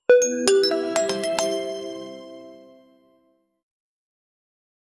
metallic-csgo-style-case--7y7suq7n.wav